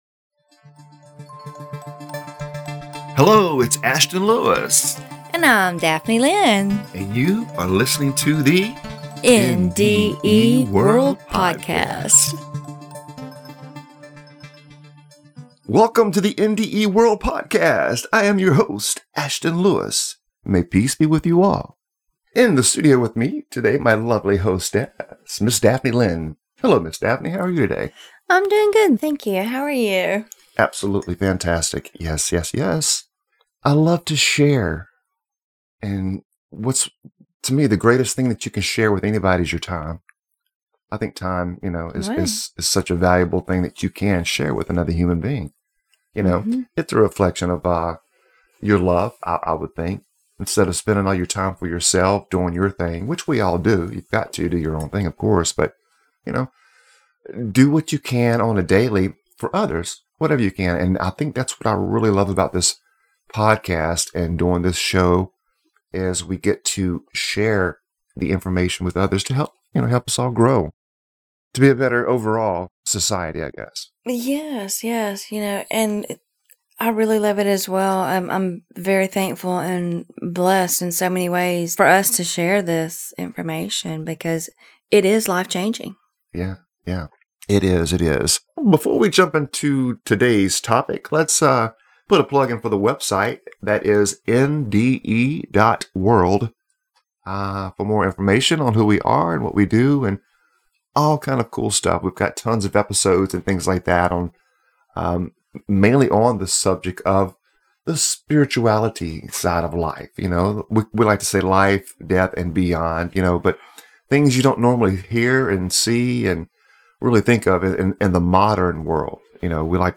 In this series, we will share the world of UDO pronounced (yoo' dō), with our listeners. UDO is the acronym for Universal Divine Oneness, and we will help you to reach that state of UDO.